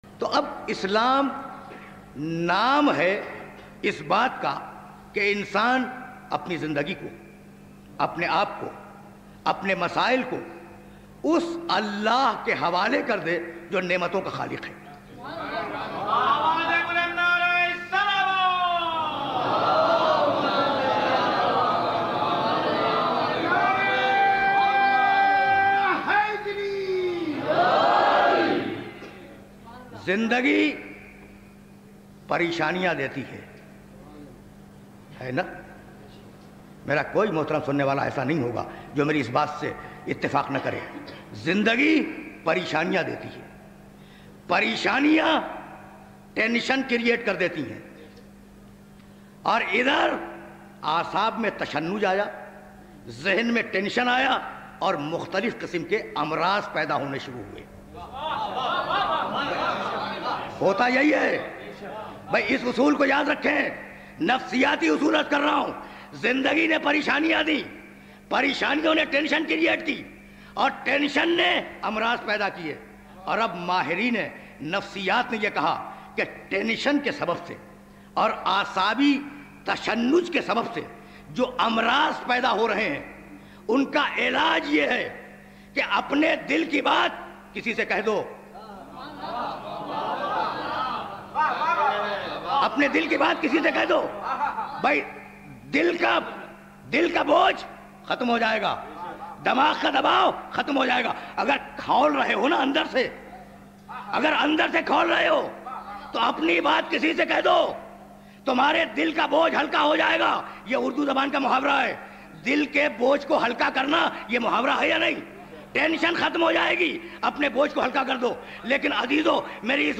اسپیکر: علامہ طالب جوہری